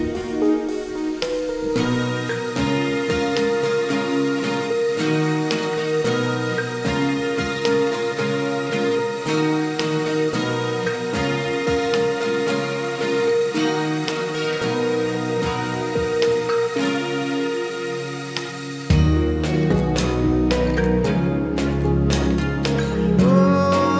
Acoustic Version Rock